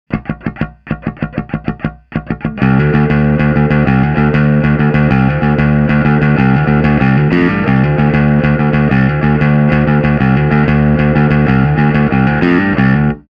An extremely versatile, warm sounding transparent overdrive.
Smog on BASS
Amp: Ampeg SVT-CL
Cab: Ampeg SVT-810E
Bass: Fender Jazz ‘66